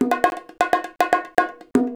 130BONGO 15.wav